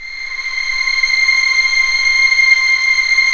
Index of /90_sSampleCDs/Roland L-CD702/VOL-1/STR_Vlns 7 Orch/STR_Vls7 p slo